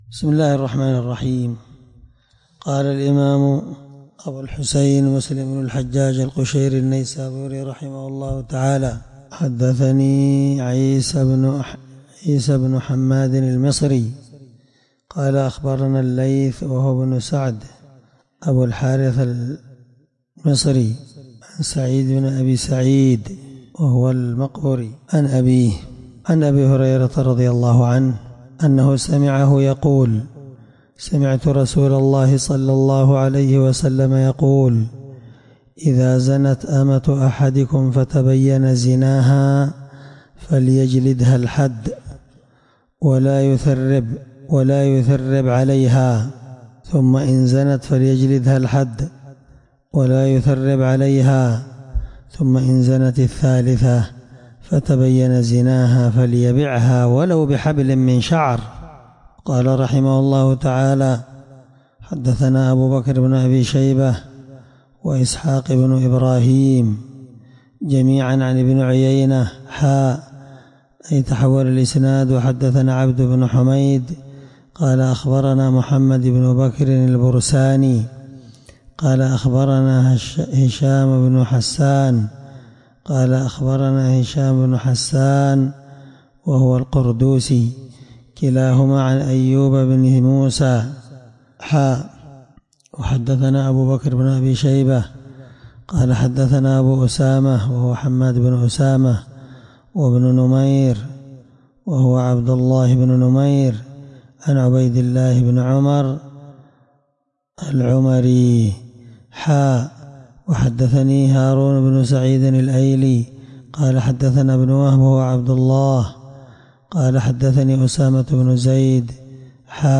الدرس14من شرح كتاب الحدود حديث رقم(1703-1704) من صحيح مسلم